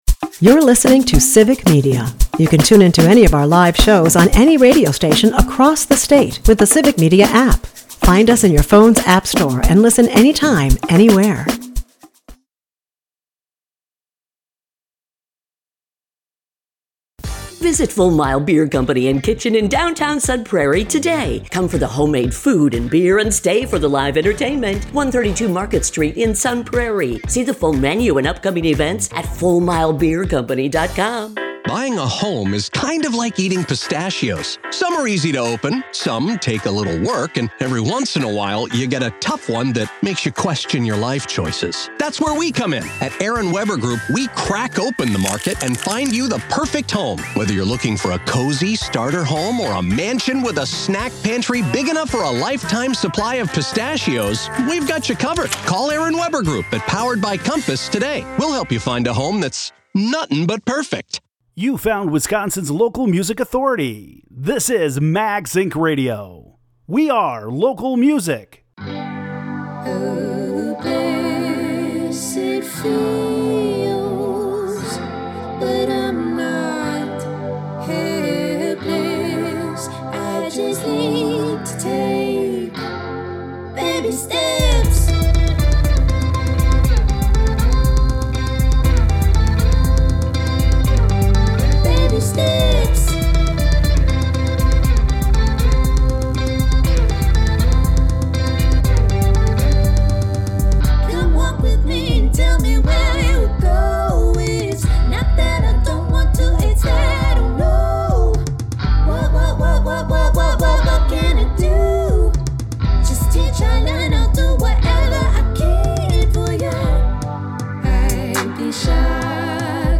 spinning Wisconsin-made music